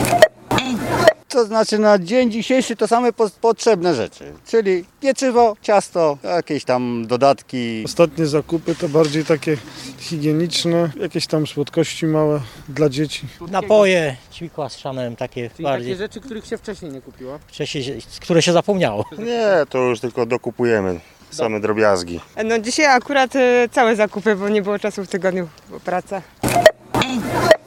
Ostatnie świąteczne zakupy [SONDA]